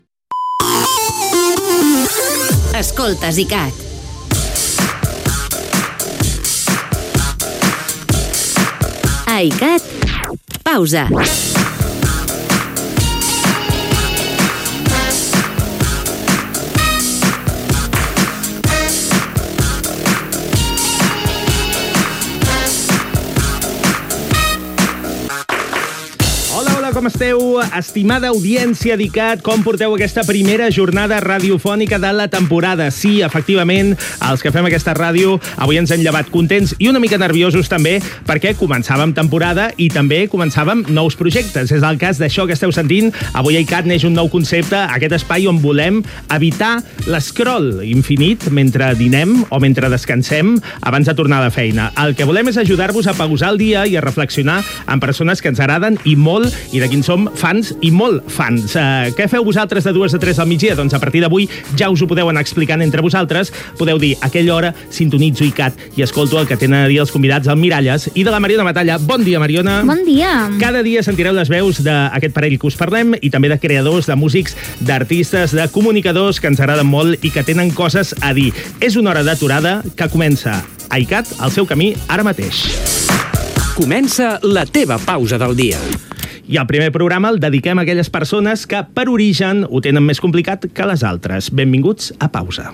Indicatiu de la ràdio, careta, presentació del primer programa, en començar la temporada 2025-2026. Continguts que s'oferiran i benvinguda